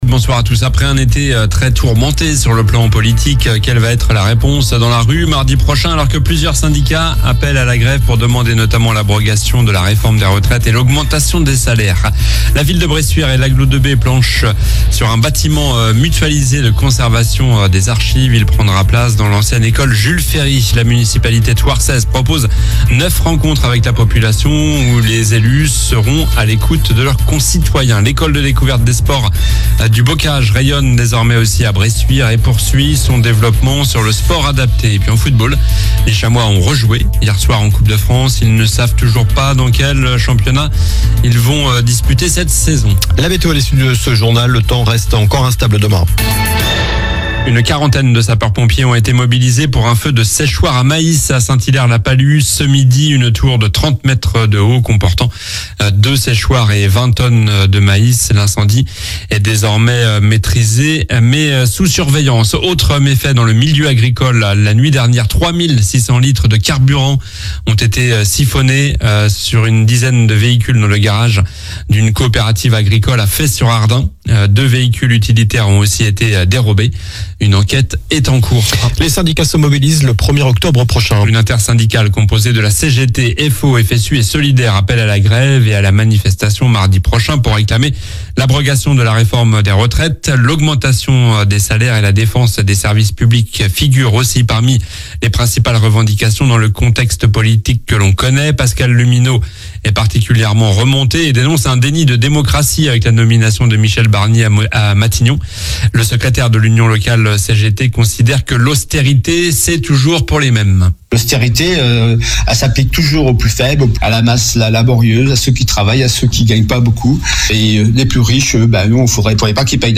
Journal du jeudi 26 septembre (soir)